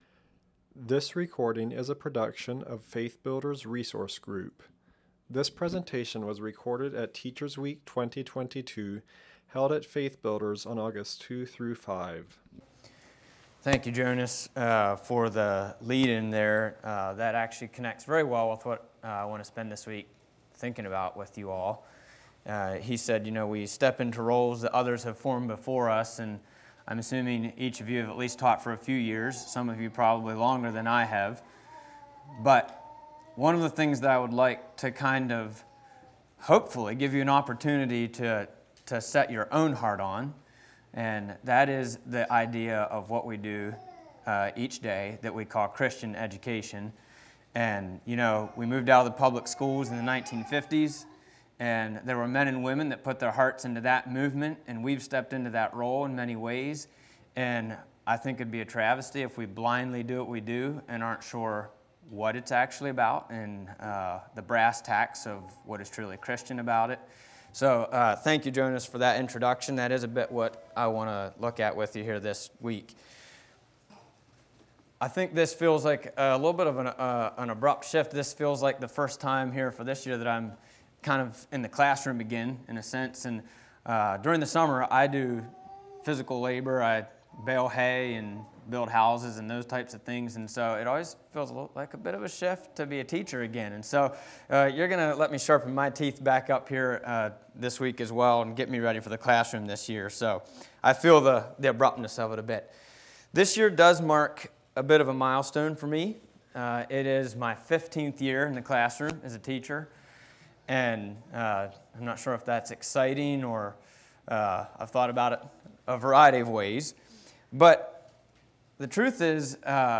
Home » Lectures » In School We Trust?